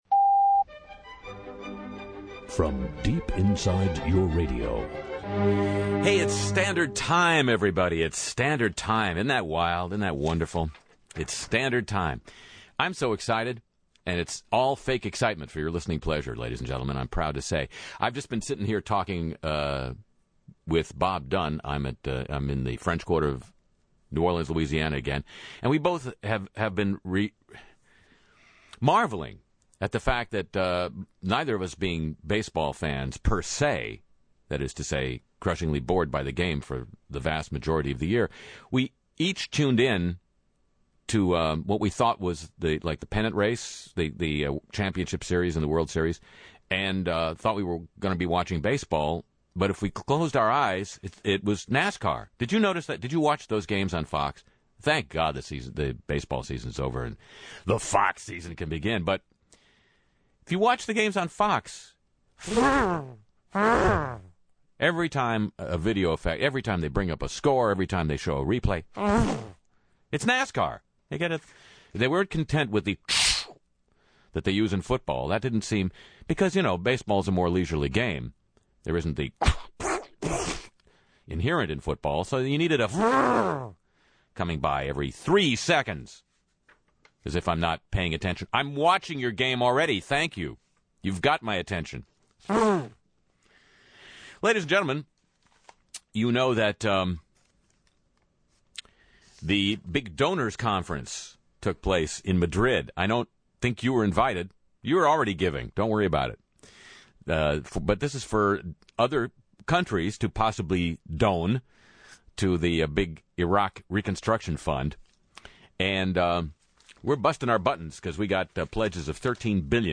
Open/ NASCAR sound effects